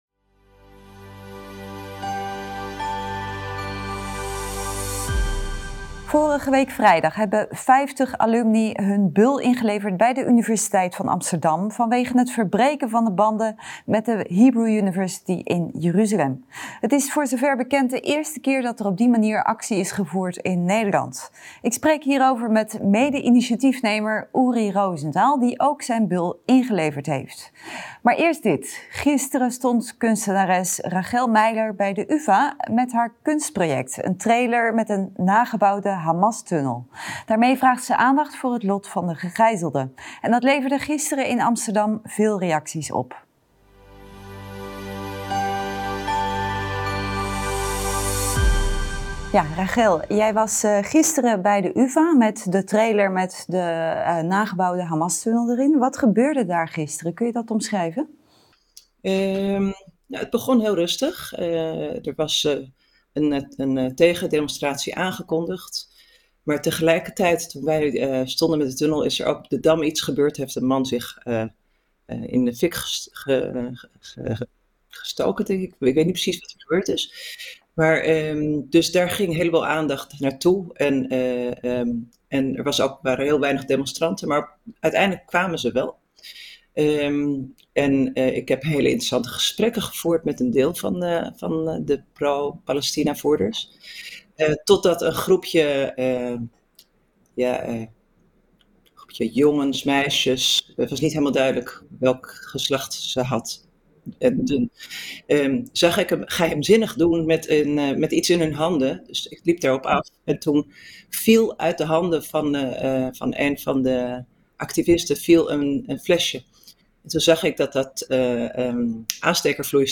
Ik spreek hierover met mede-initiatiefnemer Uri Rosenthal, die ook zijn bul ingeleverd heeft.